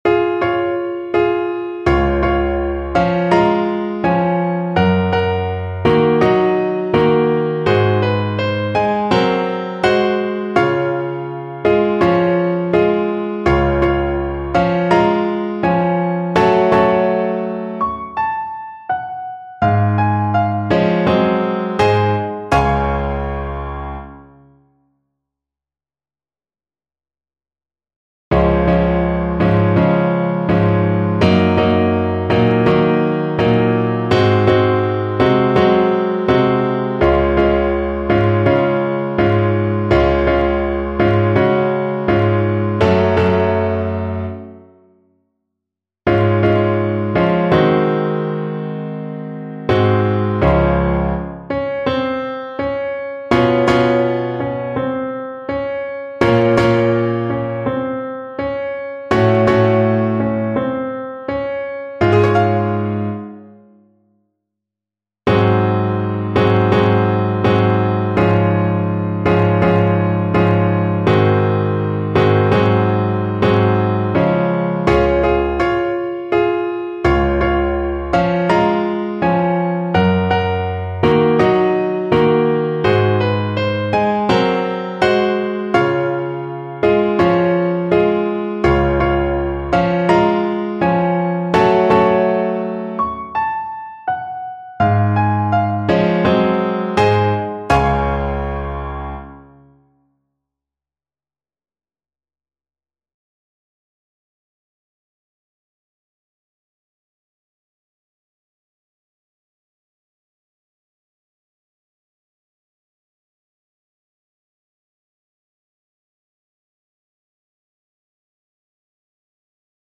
Gracioso = 60
4/4 (View more 4/4 Music)
Classical (View more Classical Trumpet Music)